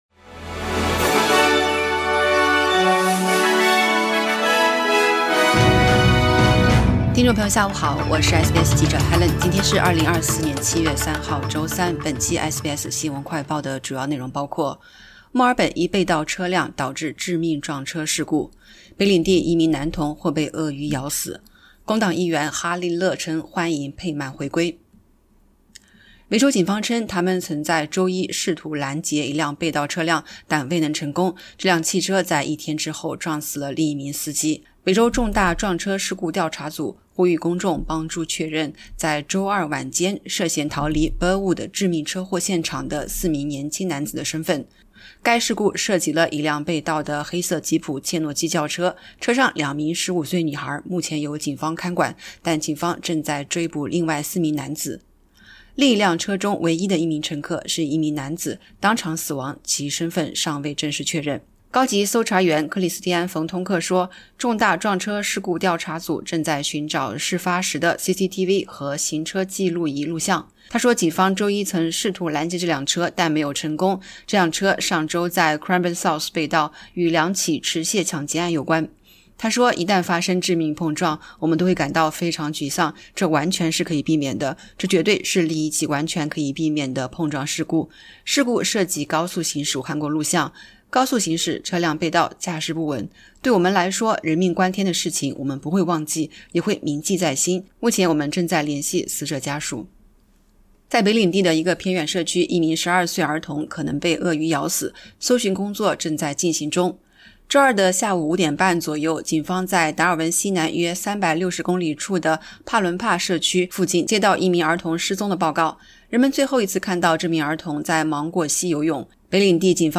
【SBS新闻快报】墨尔本一被盗车辆导致致命车祸 四人在逃